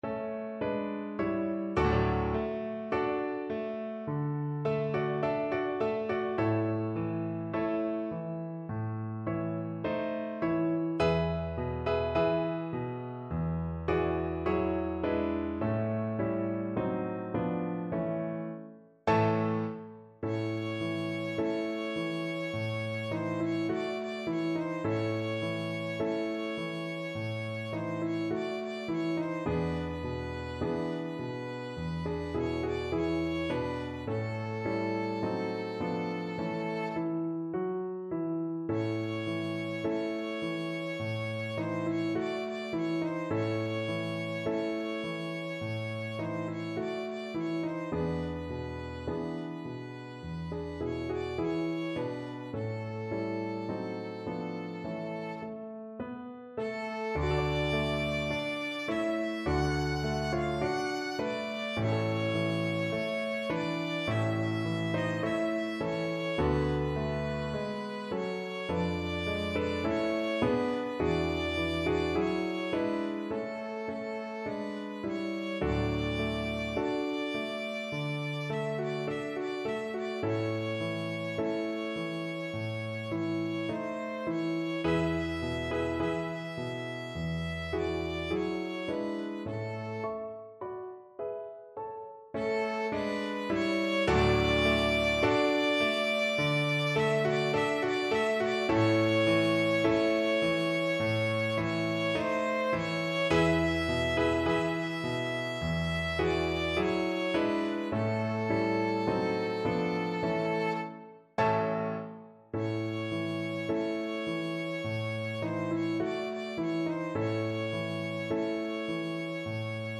2/4 (View more 2/4 Music)
A5-F#6
Andantino =c.52 (View more music marked Andantino)